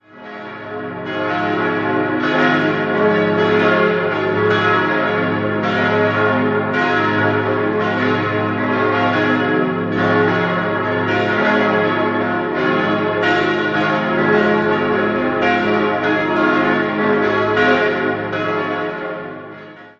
Mit 75 Metern besitzt die reformierte Kirche den höchsten Turm im Kanton Thurgau. Das Gotteshaus wurde im Jahr 1892 eingeweiht. 5-stimmiges erweitertes A-Dur-Geläute: a°-cis'-e'-fis'-a' Die zweitkleinste Glocke wurde 1962, die anderen bereits im Jahr 1892 von der Firma Rüetschi in Aarau gegossen.